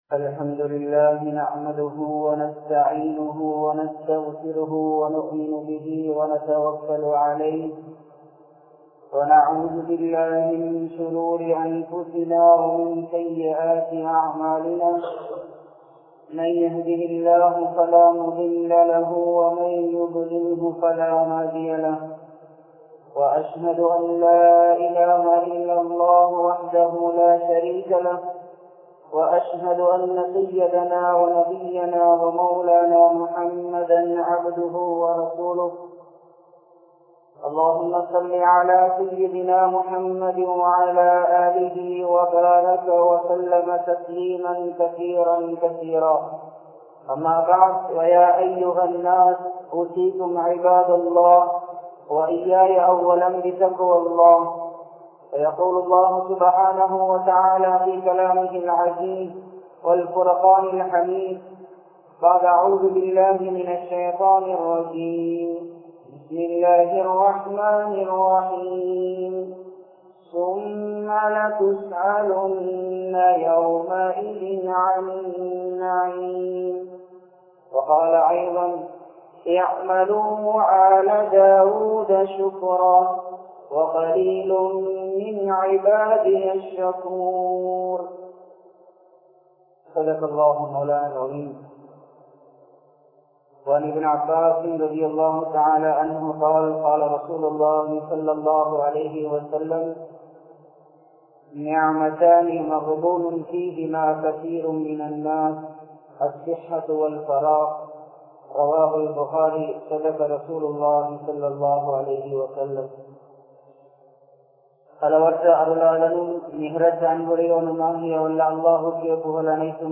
Allah Thanthulla Arutkodaihal (அல்லாஹ் தந்துள்ள அருட்கொடைகள்) | Audio Bayans | All Ceylon Muslim Youth Community | Addalaichenai
Majmaulkareeb Jumuah Masjith